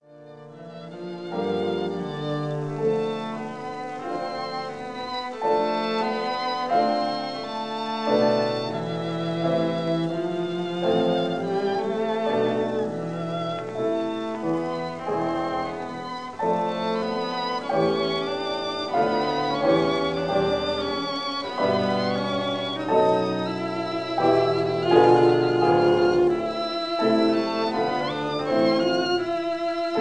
violin
cello